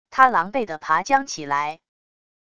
他狼狈的爬将起来wav音频生成系统WAV Audio Player